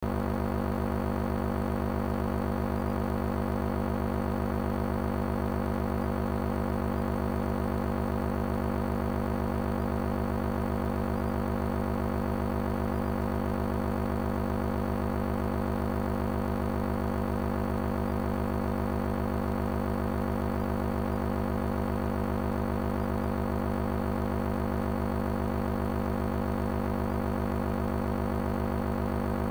б). РБУ -- Москва, 10 кВт, 66,6 кГц.
Пример сигнала.
RBU_66.6kHz.mp3